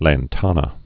(lăn-tänə, -tănə)